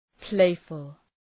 Προφορά
{‘pleıfəl}